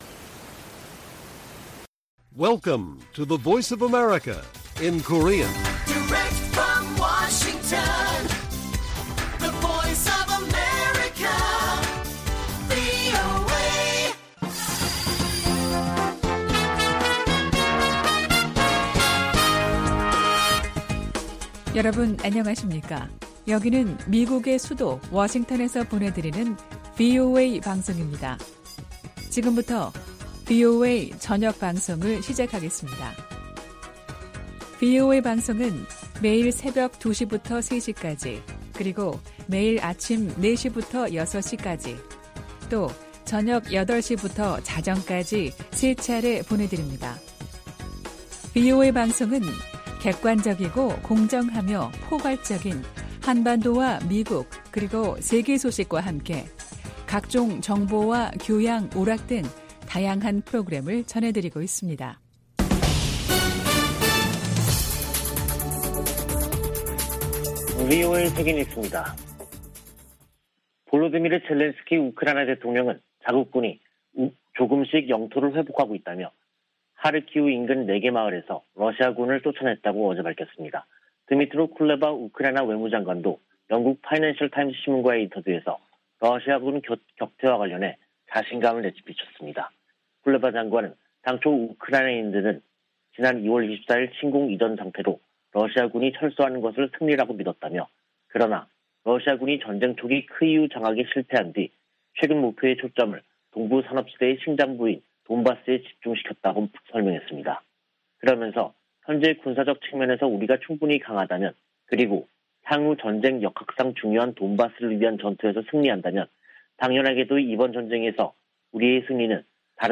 VOA 한국어 간판 뉴스 프로그램 '뉴스 투데이', 2022년 5월 11일 1부 방송입니다. 윤석열 한국 신임 대통령이 취임사에서 북한의 무력시위에 대한 언급 없이 비핵화 전환을 조건으로 경제협력 의지를 밝혀 신중한 입장을 보였다는 평가가 나오고 있습니다. 미 국무부는 한국 새 정부와 긴밀히 조율해 북한의 위협에 대응할 것이라고 밝혔습니다. 여러 나라가 한국 새 대통령에게 축하 메시지를 보내며 빠른 시일 내 정상 간 만남을 기대한다고 밝혔습니다.